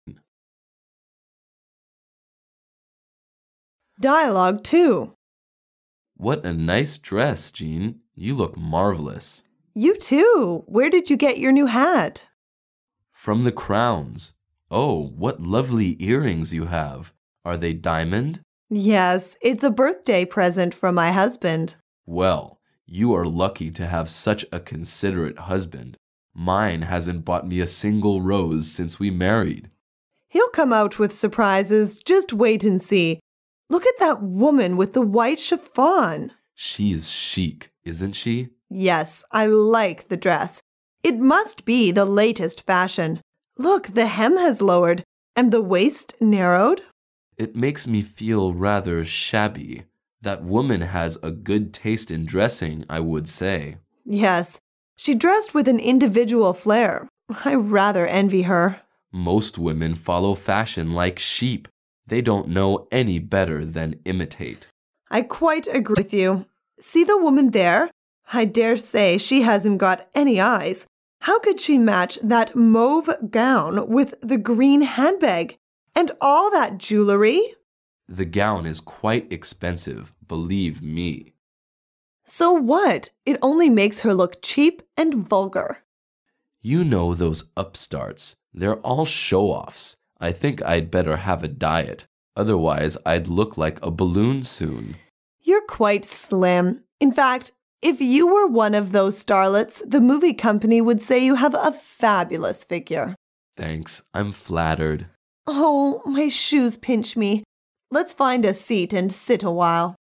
Dialouge 2